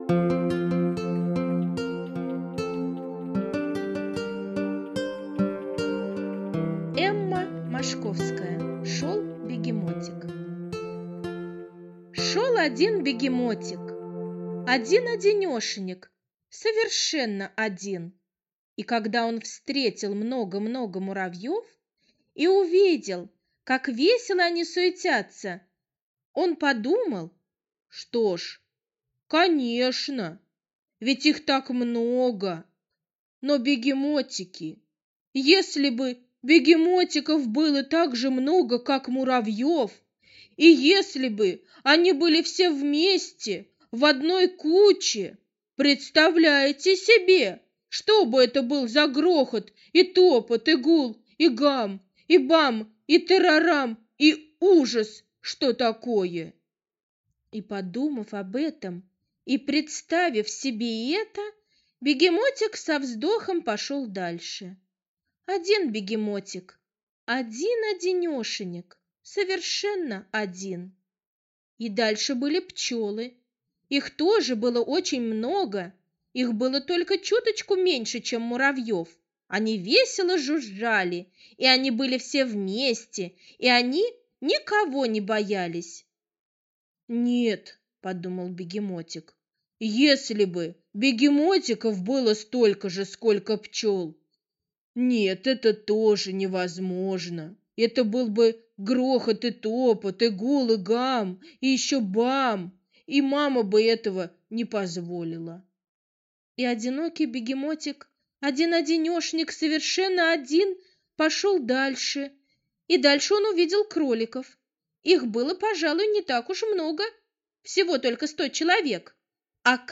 Шел бегемотик - аудиосказка Мошковской Э.Э. Сказка про Бегемотика, которому было скучно одному.